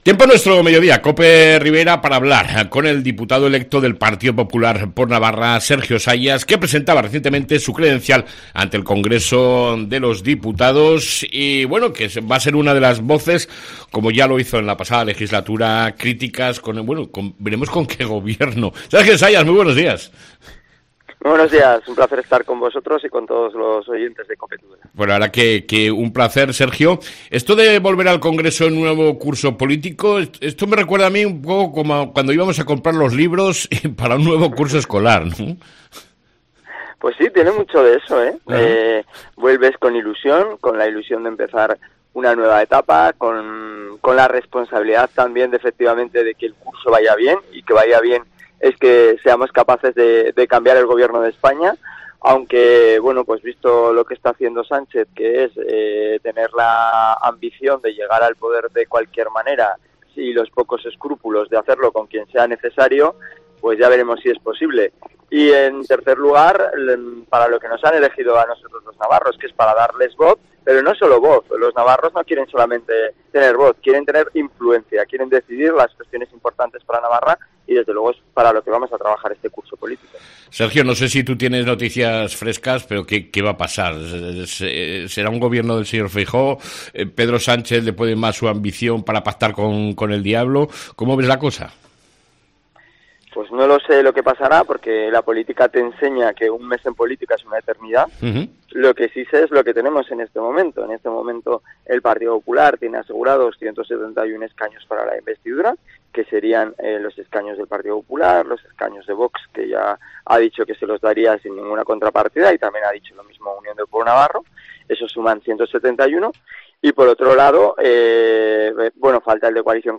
ENTREVISTA CON EL DIPUTADO NAVARRO Y RIBERO DEL PPN, SERGIO SAYAS